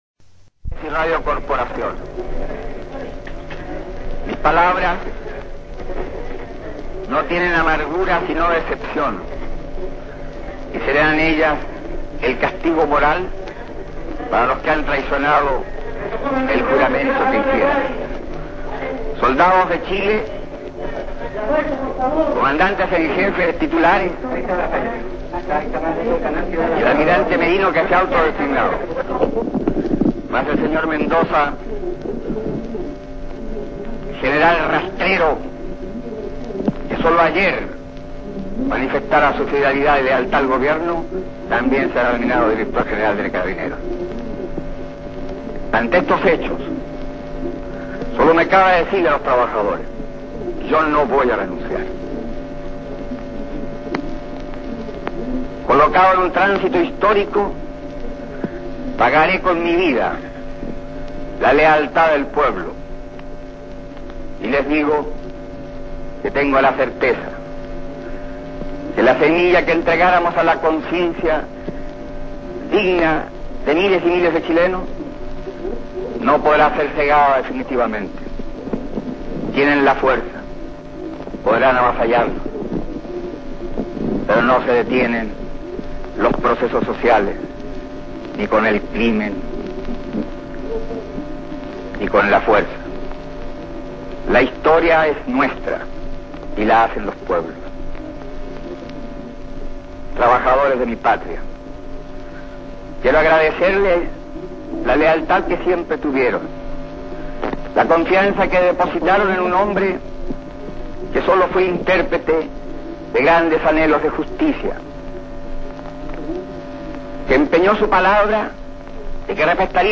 Em seguida todos os presentes ouviram a última fala do Presidente Salvador Allende.